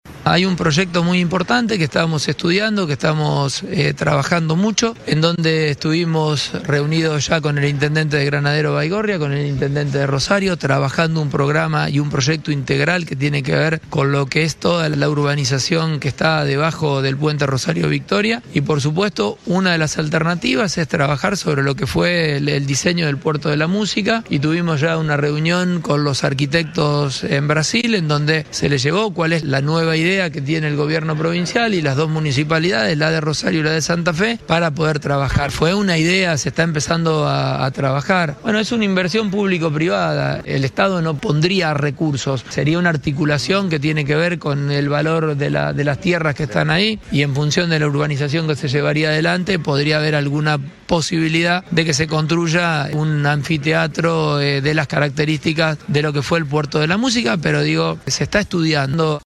El nuevo impulso al proyecto en el parque de la Cabecera fue parte de las declaraciones a la prensa del propio gobernador Maximiliano Pullaro ayer en Santa Fe.
MAXIMILIANO-PULLARO-Gobernador-de-Santa-Fe.-Puerto-de-la-Musica.mp3